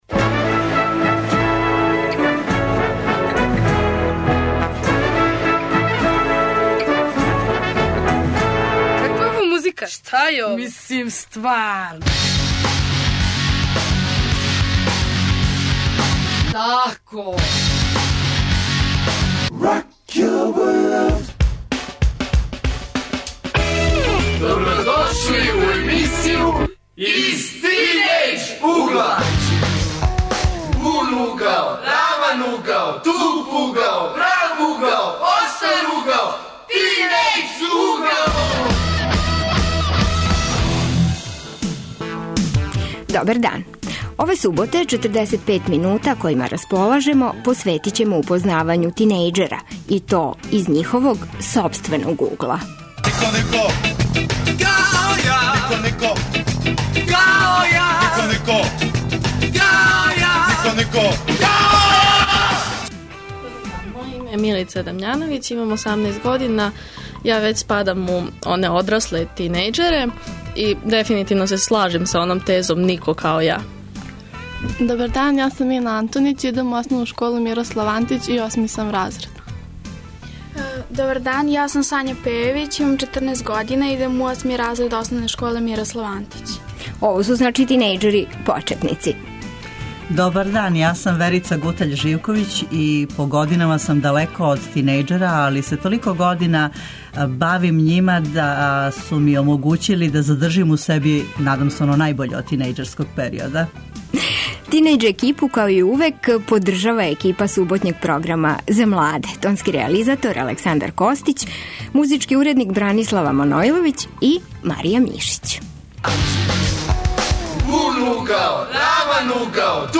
Које особине се највише цене и шта сматрају да је данас најважније?О томе разговарамо са тинејџерима који ће бити гости емисије.